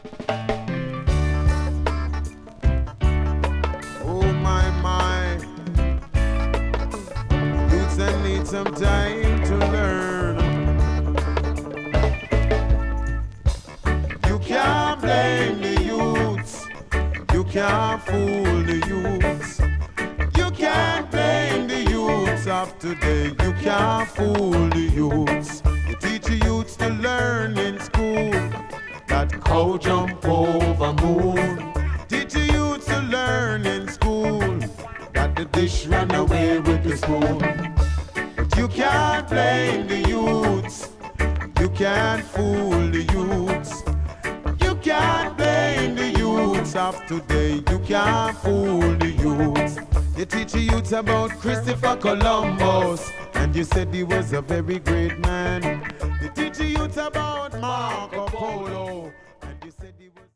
the version is a full live riddim in finest style.
BIG voice BIG lyrics BIG version.